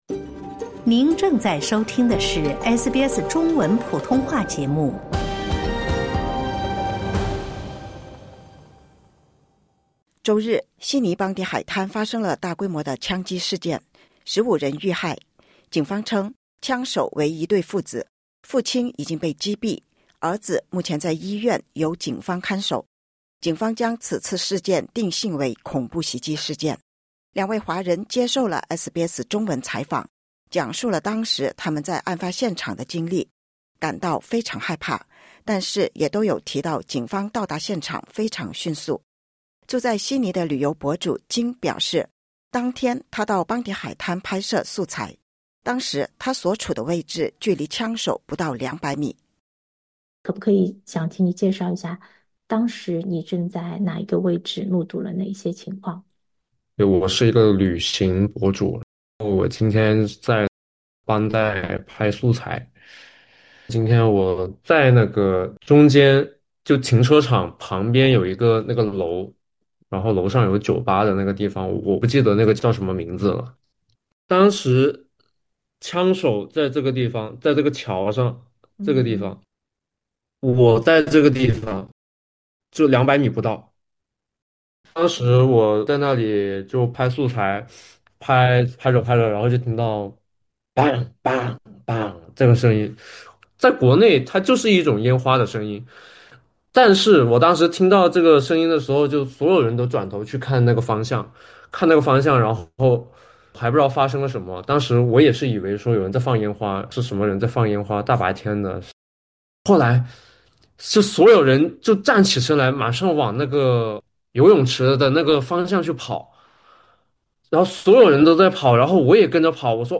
两位华人接受了SBS中文采访，讲述当时他们在案发现场的经历，一开始听到枪响时，他们还以为是放烟花。